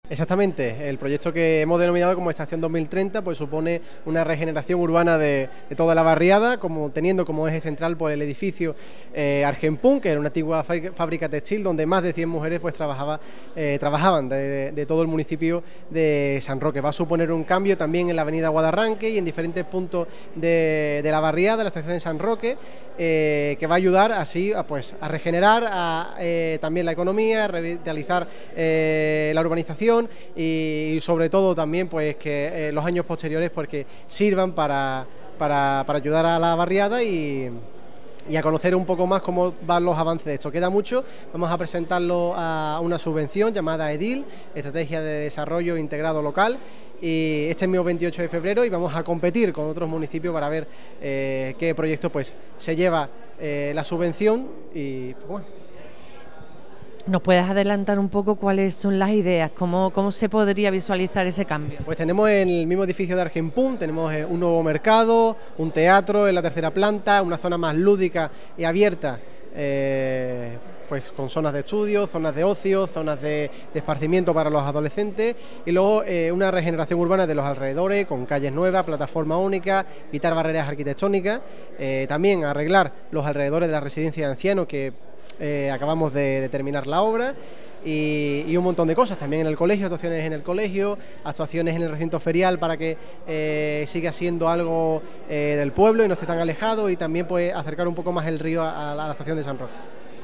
La segunda reunión informativa, sobre el futuro de la finca de la antigua fábrica Argenpunt, se desarrolló en la tarde de este martes en la antigua estación de Renfe donde se había convocado a los vecinos y vecinas de la Estación de San Roque. Durante el encuentro se informó de los nuevos avances sobre el proyecto del citado edificio y sus alrededores dentro del el proyecto “Estación 2030”.